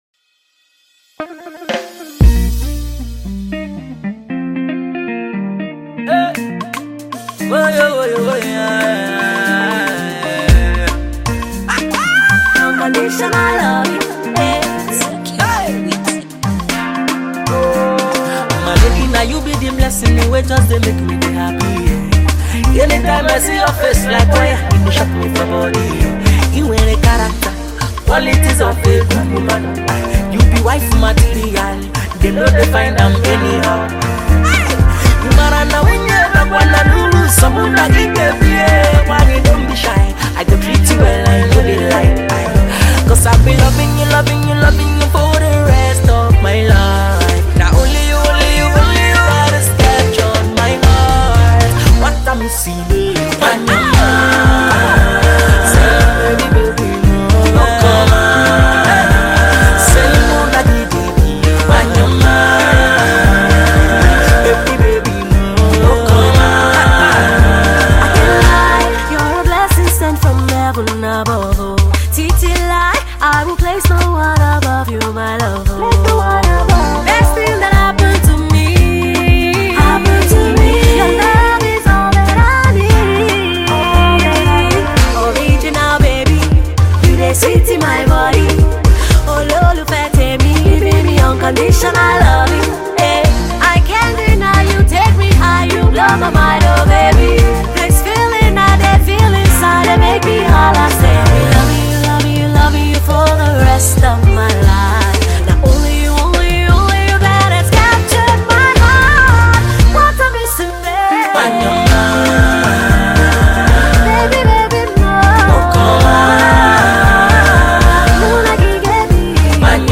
March 18, 2025 Publisher 01 Gospel 0